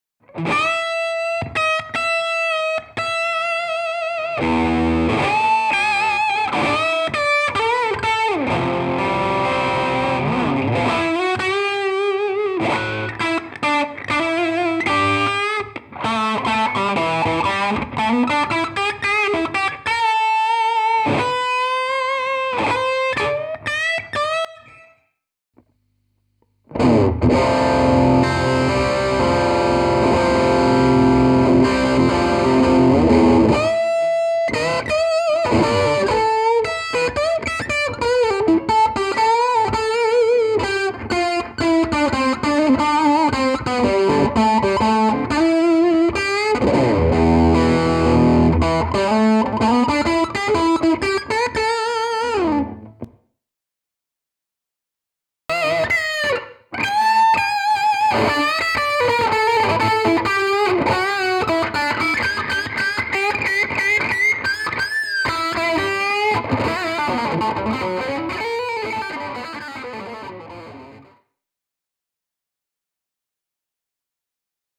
classez par ordre de preference, le son 1 , 2 , ou 3
PS : c'est un son "bright', tres bright, je suis fan de ça, pensez van halen 1 ou encore ce type de son, ou l'aigu et super present
PS 2 : oui j'suis pas tres accordé je sais ! lol
le second est tout pourri ( résonance bizarroïde dans bas medium )
le 3 ressemble au 1 mais en moins bon (légèrement moins de grain )